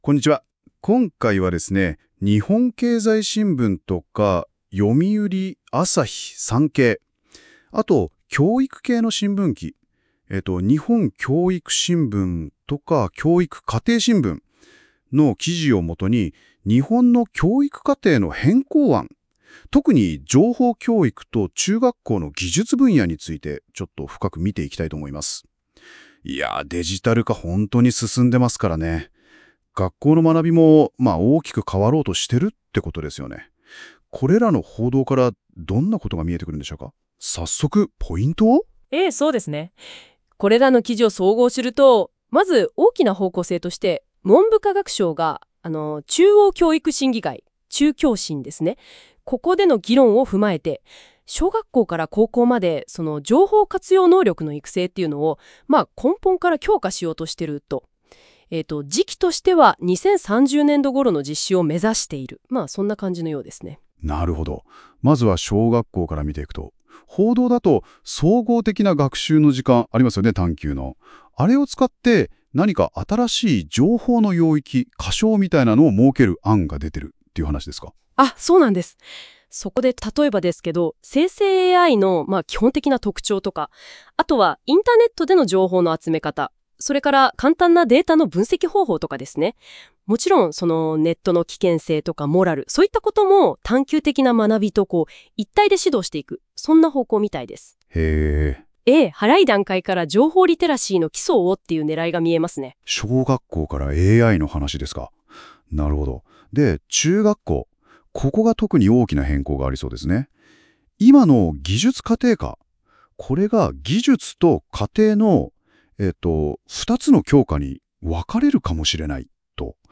・かりしょう→かしょう，学習指導要領の解説→学習指導要領 など一部表現に誤りがありますので，ご注意ください。